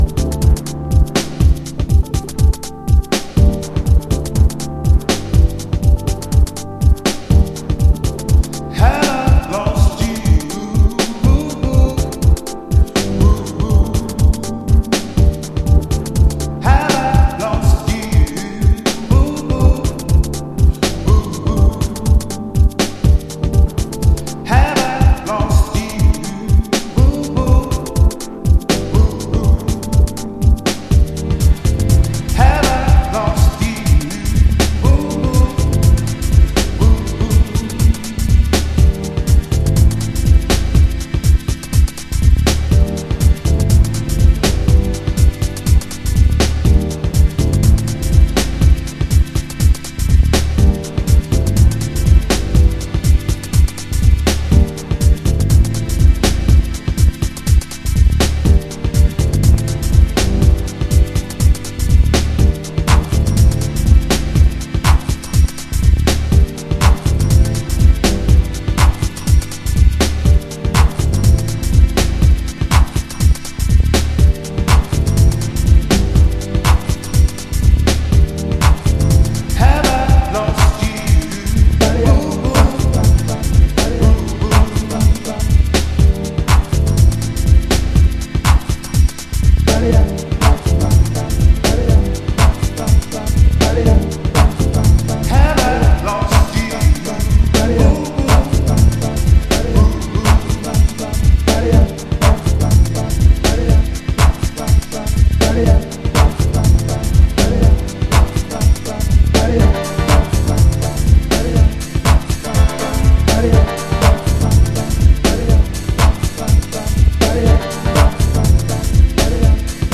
この時期の荒々しさと浮遊感が同居したサウンドはたまんないですね。
Urban Instrumental
Chicago Oldschool / CDH